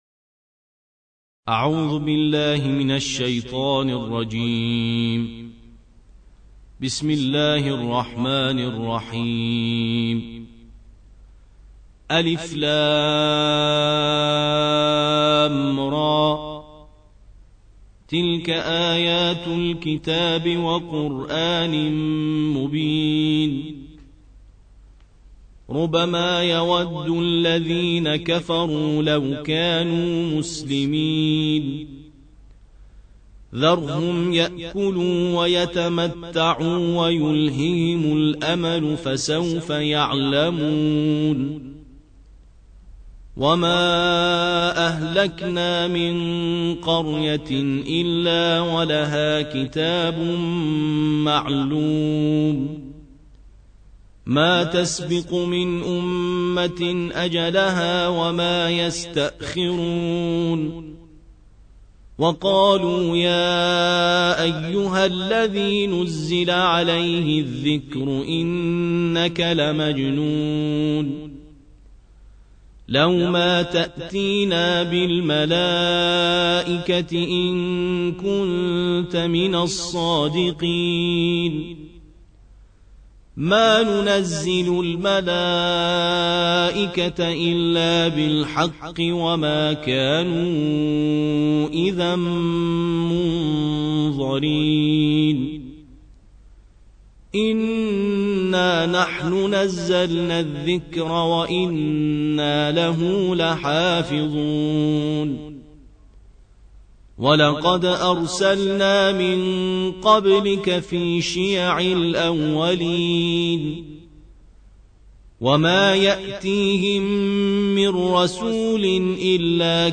الجزء الرابع عشر / القارئ